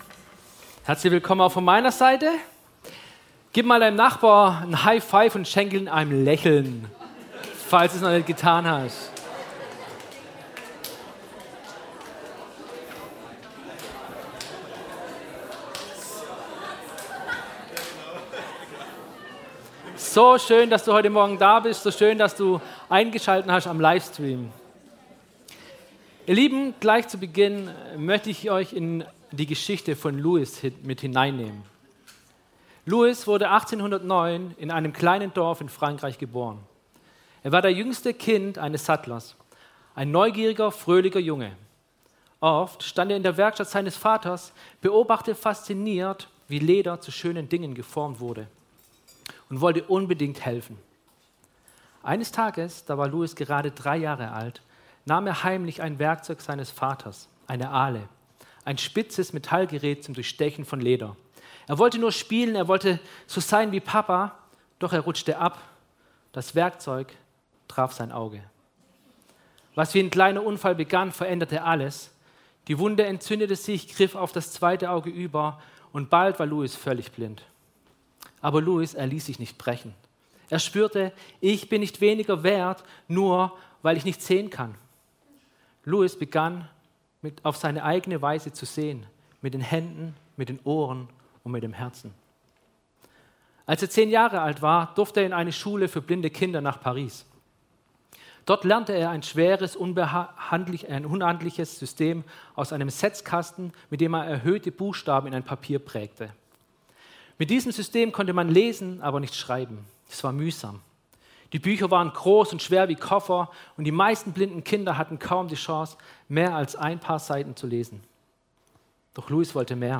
Nehemia Dienstart: Gottesdienst - Sonntagmorgen Apostelgeschichte 29 Apostelgeschichte 29 Teil 5